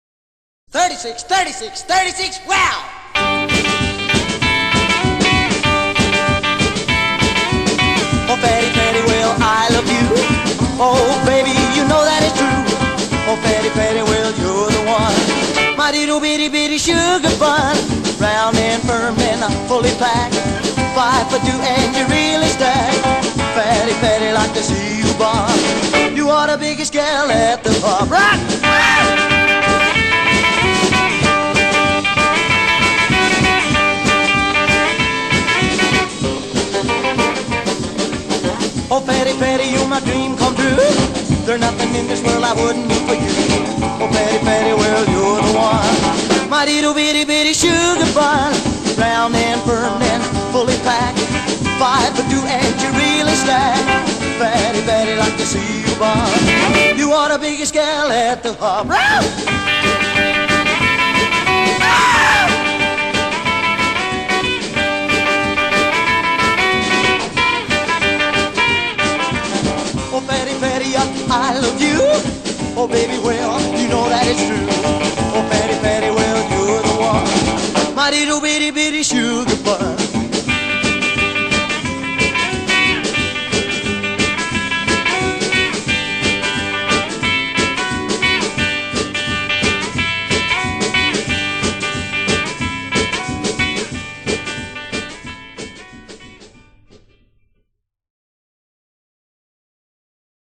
rhythm guitar and slap bass
lead guitar
drums
in a Denver basement